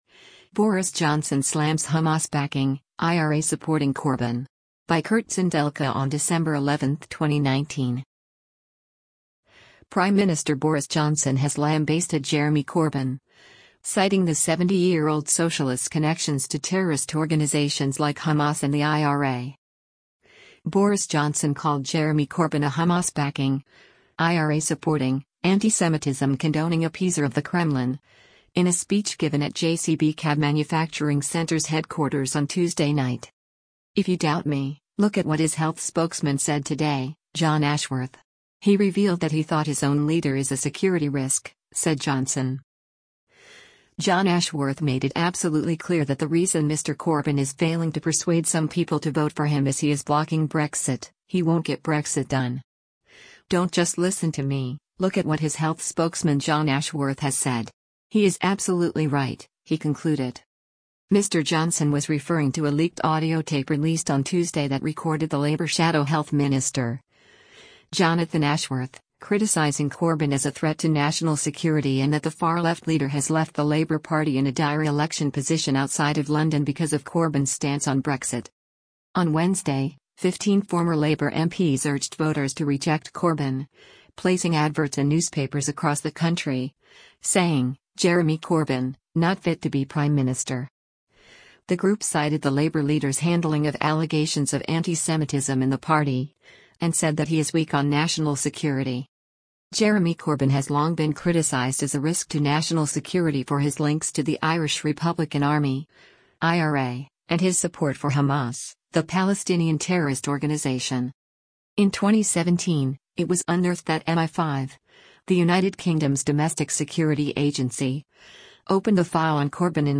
Boris Johnson called Jeremy Corbyn a “Hamas-backing, IRA-supporting, antisemitism-condoning appeaser of the Kremlin”, in a speech given at JCB Cab Manufacturing Centre’s headquarters on Tuesday night.